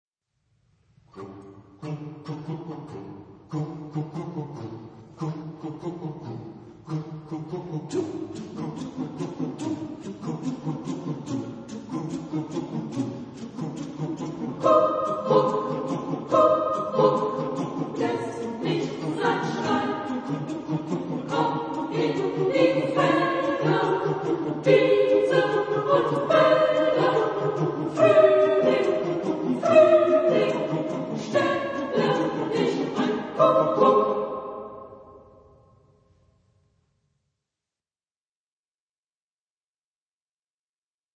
Type of Choir: SATB  (4 mixed voices )
Tonality: G major